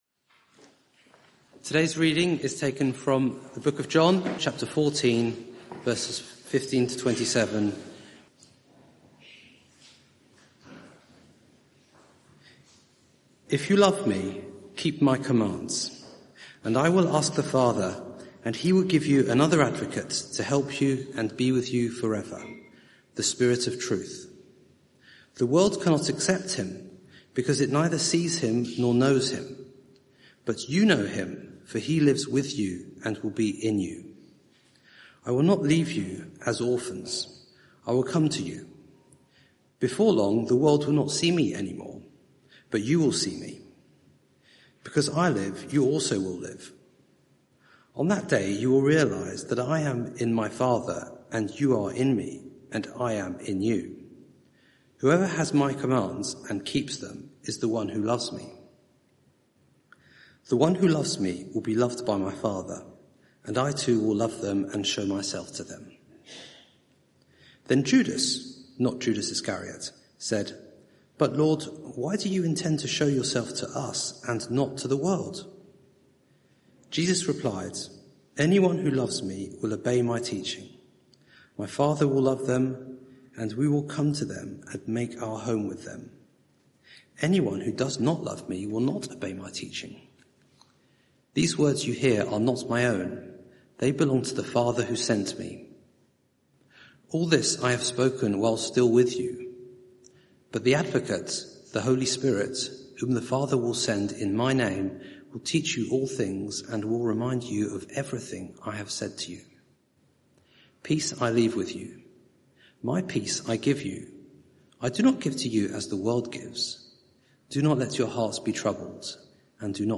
Sermon (audio) Search the media library There are recordings here going back several years.